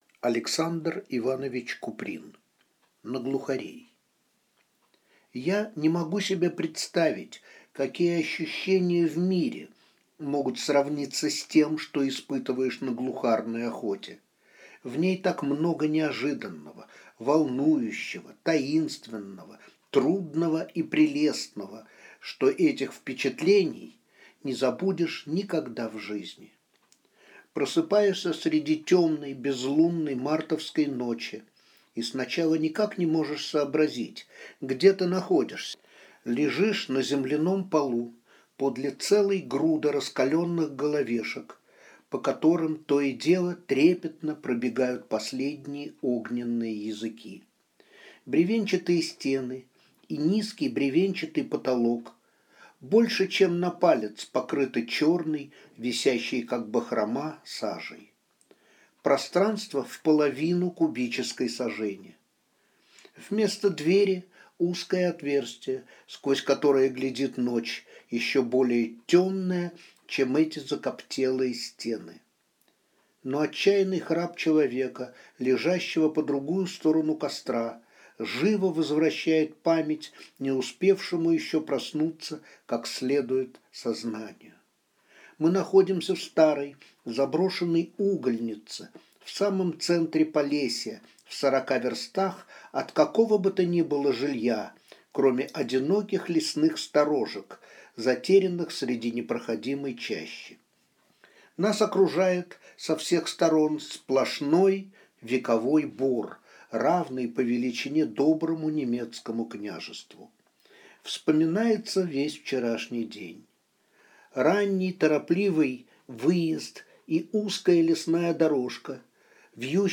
Aудиокнига На глухарей